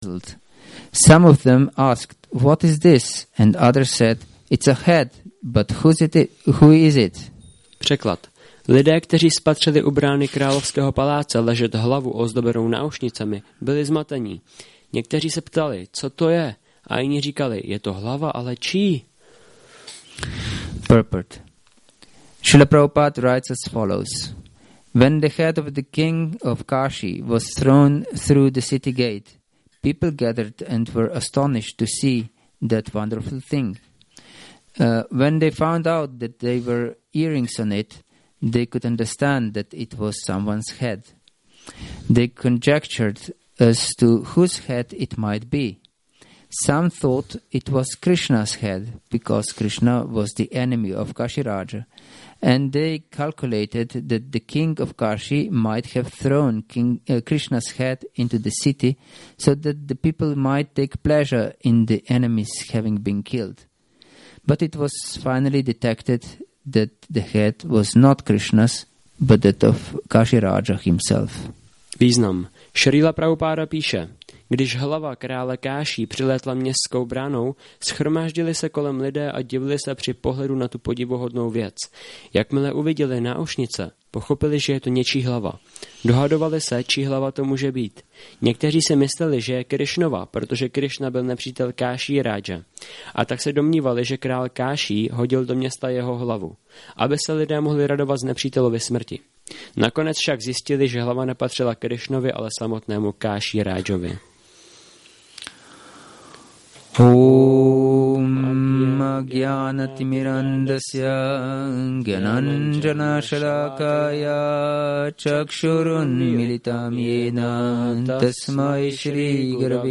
Šrí Šrí Nitái Navadvípačandra mandir
Přednáška SB-10.66.25